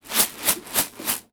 R - Foley 97.wav